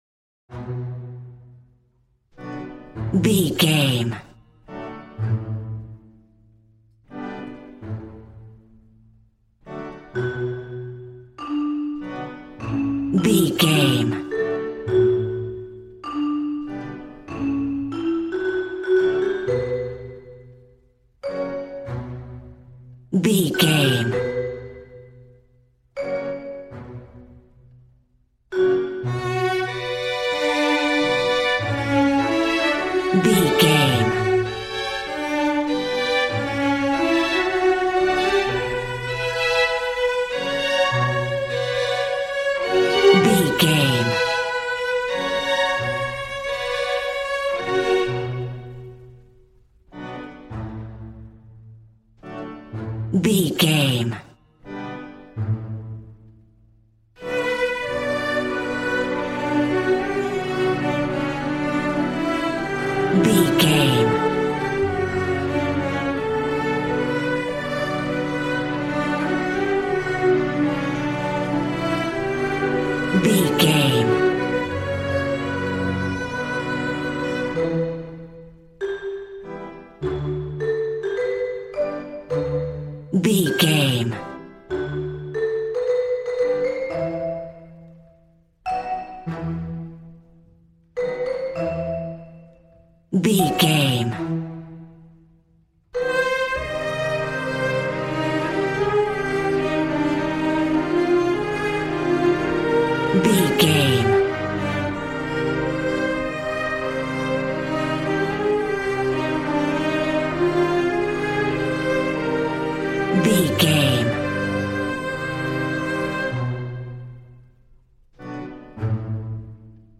Aeolian/Minor
cheerful/happy
joyful
drums
acoustic guitar